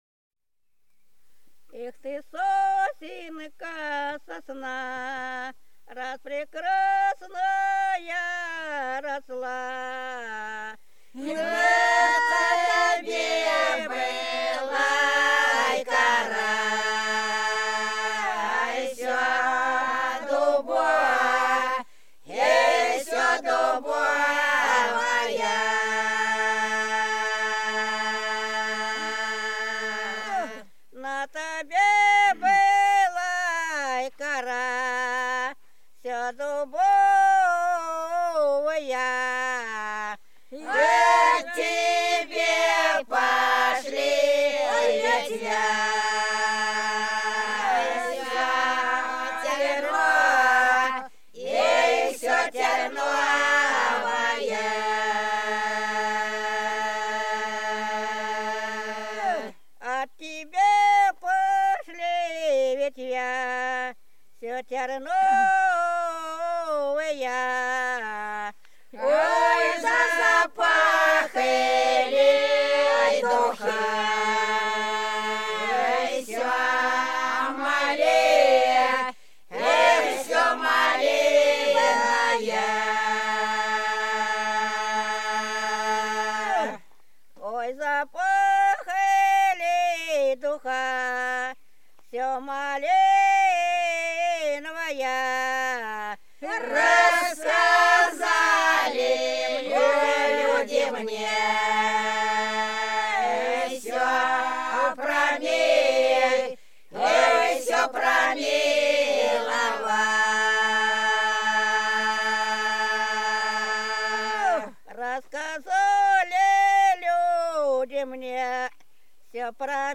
За речкою диво Ой ты, сосенка моя - протяжная (с. Пузево)
04_Ой_ты,_сосенка_моя_-_протяжная.mp3